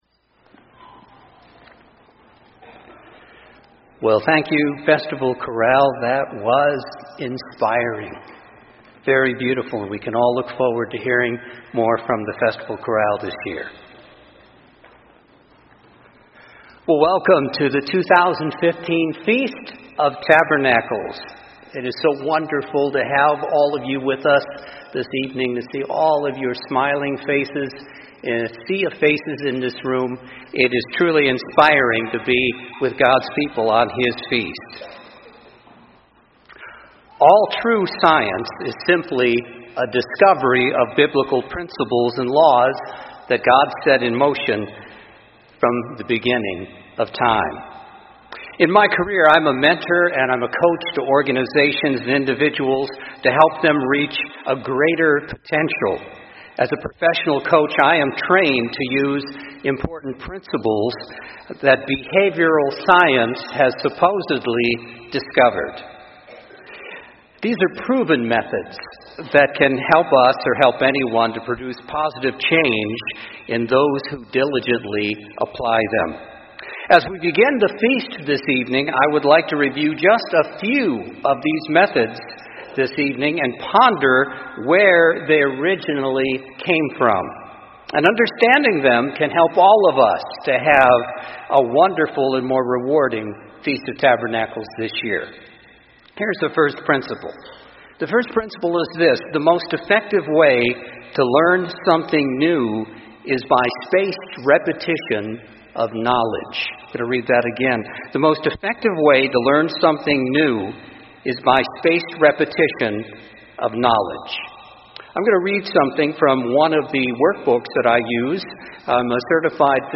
This sermon was given at the Panama City Beach, Florida 2015 Feast site.